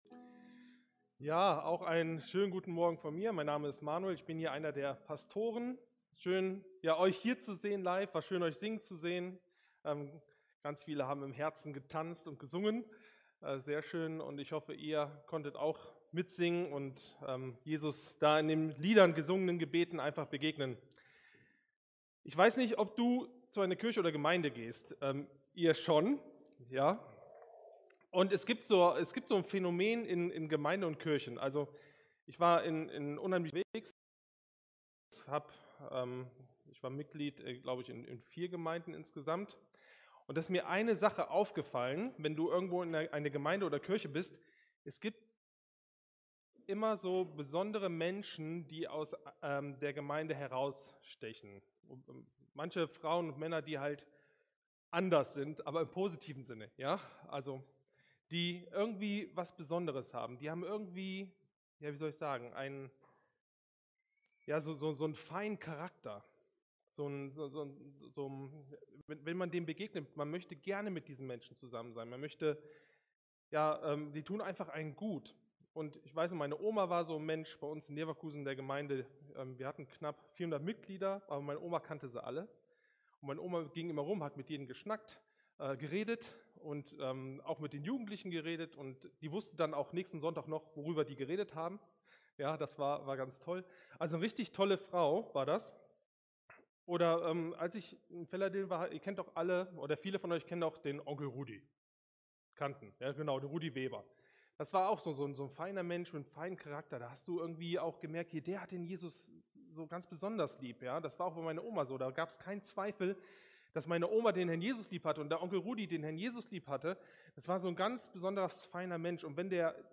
Gemeindeleitung Church Leadership Gottesdienst